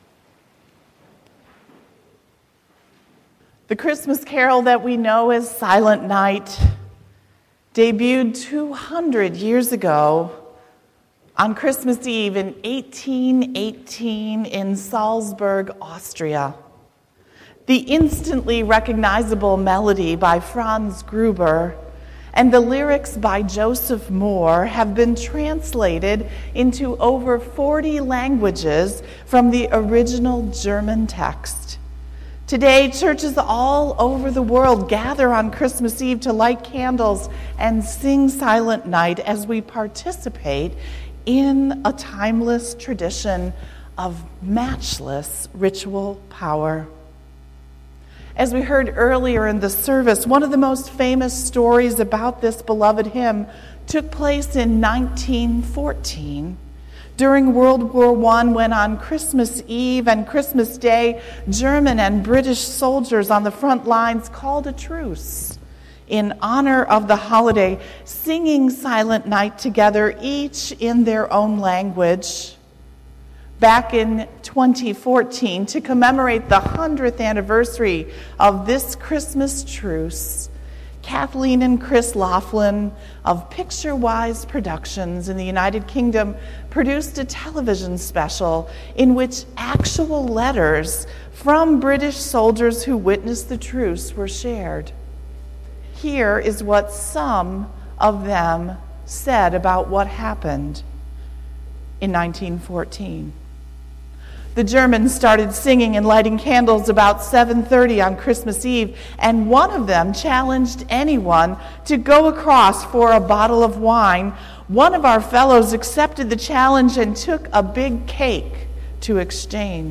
Dec0218-Sermon.mp3